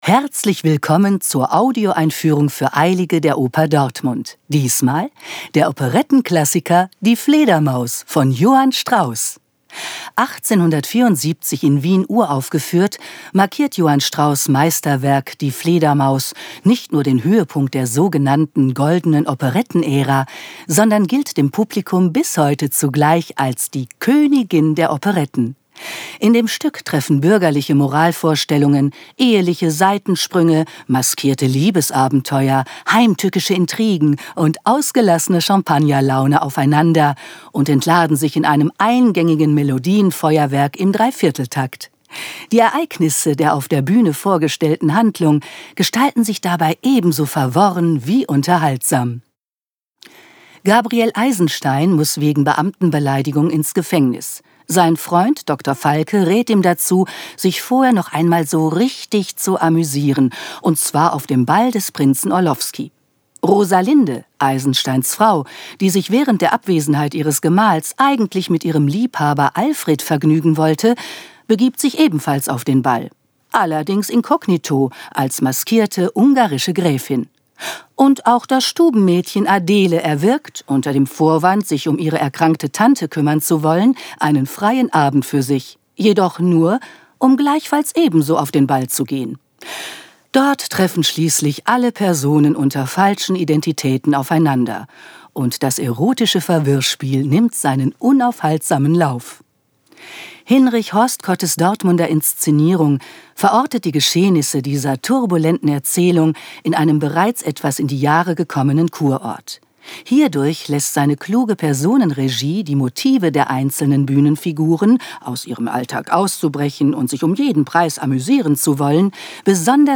tdo_Audioeinfuehrung_Die_Fledermaus.mp3